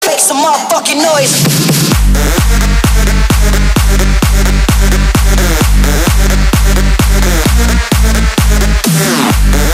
громкие
dance
Bass
Bass - Dance - Громкий